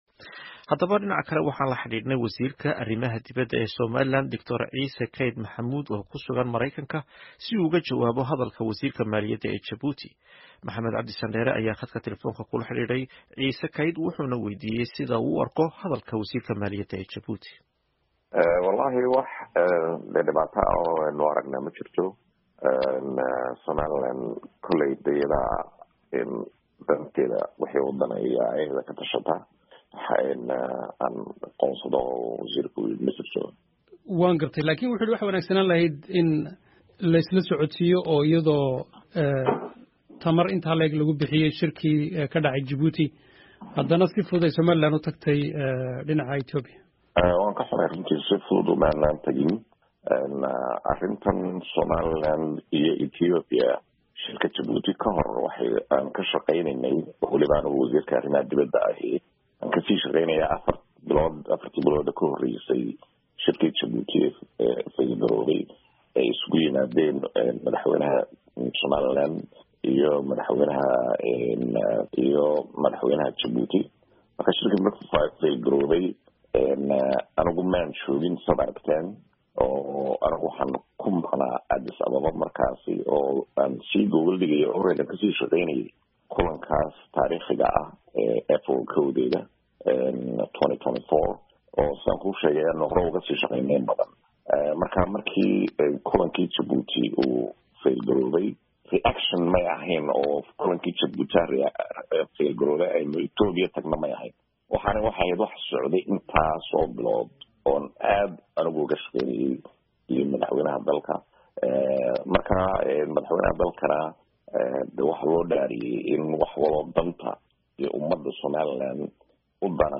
Wareysiga Wasiirka Arrimaha Dibedda Somaliland